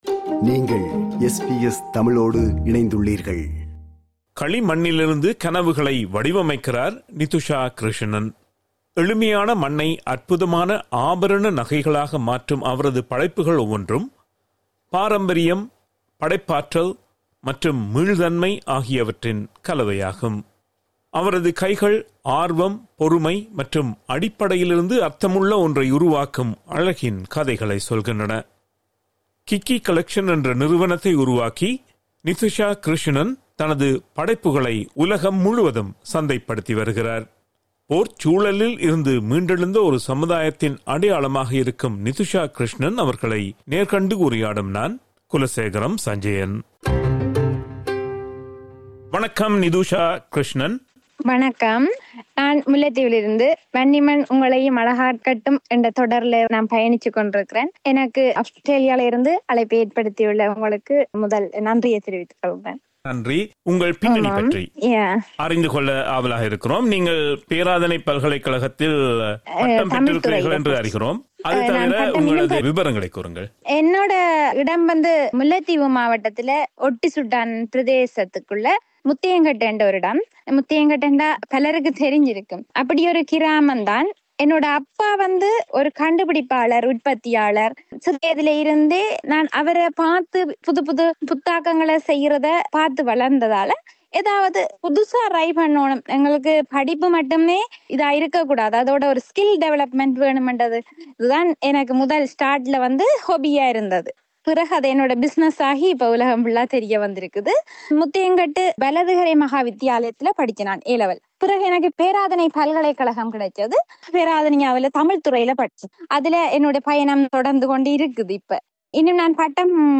நேர்காண்கிறார்